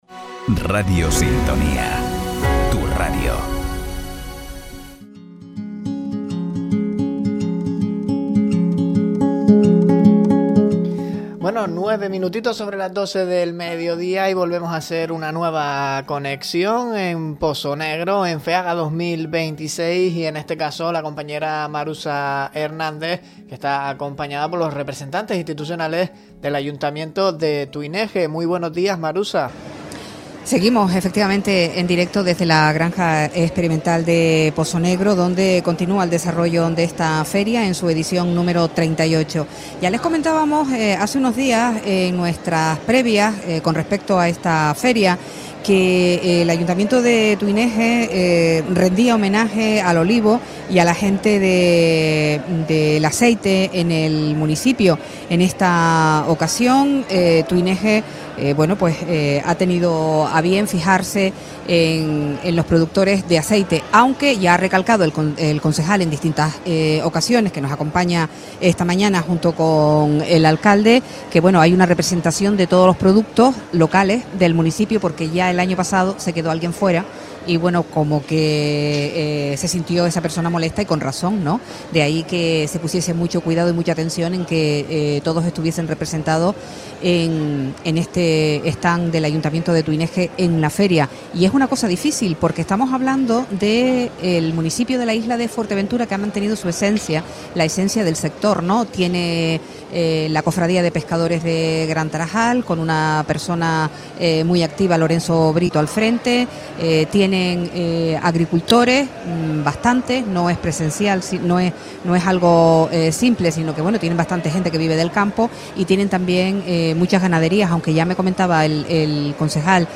David Hernández, alcalde de Tuineje y Jonathan Peña, concejal del sector primario del municipio pasan por los micrófonos de Radio Sintonía en Feaga 2026 - Radio Sintonía
Alcalde y concejal analizan la situación del sector primario del municipio de Tuineje en el set de Radio Sintonía en Feaga Deja un comentario
Entrevistas